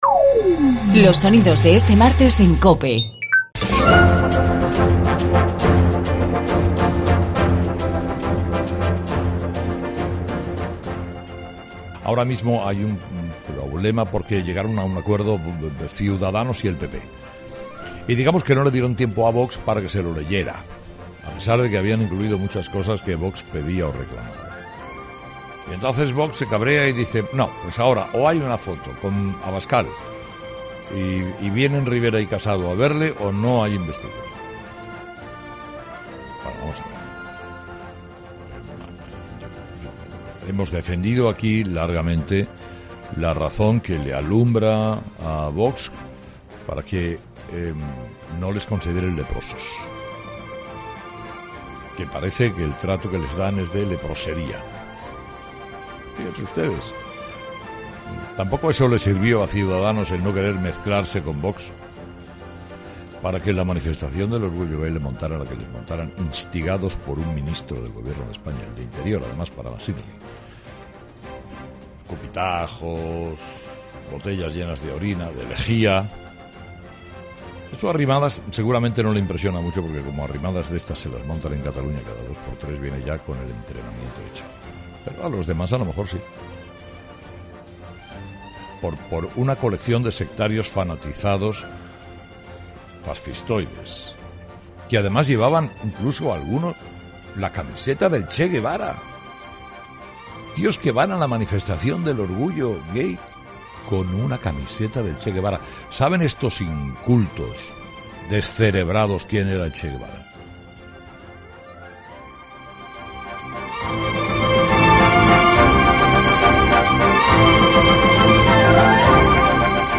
En su monólogo de este martes, Herrera ha emplazado a PP, Cs y Vox a encontrar un acuerdo para que la Comunidad de Madrid sea gobernada por las fuerzas de la derecha y no caiga en poder de los socialistas.
También ha sido entrevistada la candidata del PP a la Presidencia de la Comunidad de Madrid, Isabel Díaz Ayuso, que ha dicho que “el acuerdo con Cs recoge peticiones de Vox" en un "guiño a la estabilidad”.